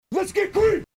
Tags: humor funny sound effects sound bites radio